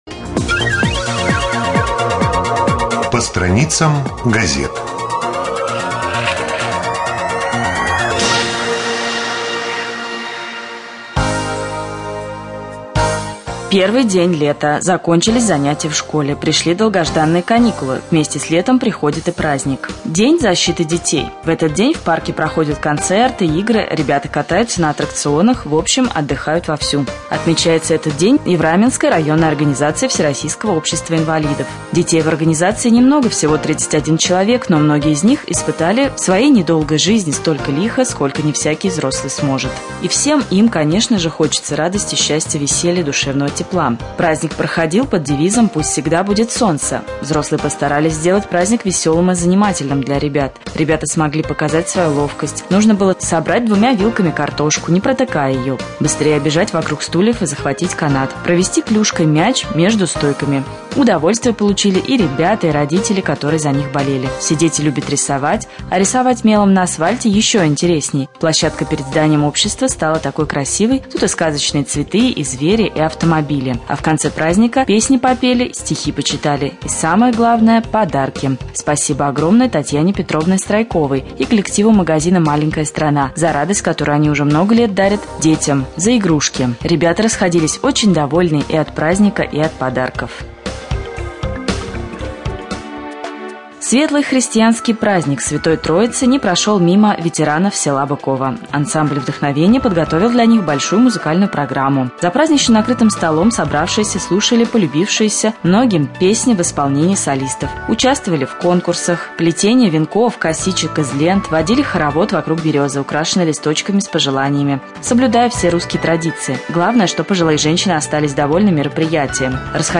1.Рубрика «По страницам прессы». Новости